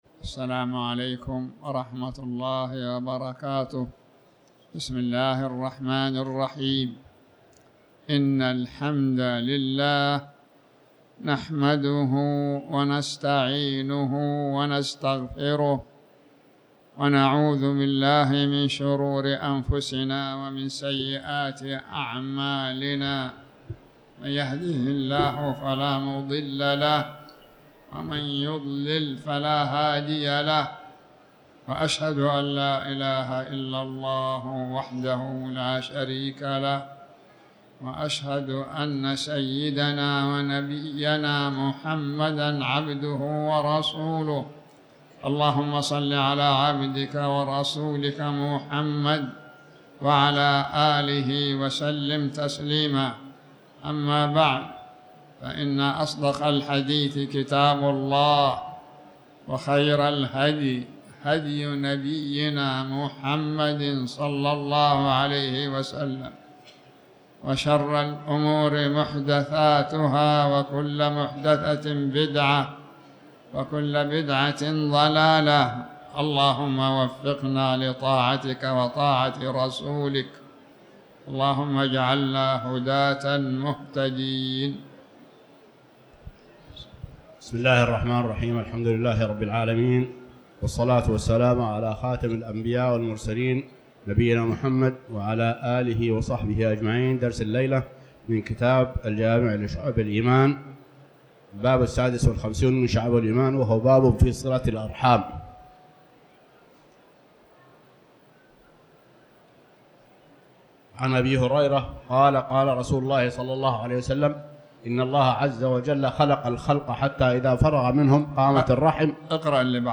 تاريخ النشر ٢٥ ربيع الثاني ١٤٤٠ هـ المكان: المسجد الحرام الشيخ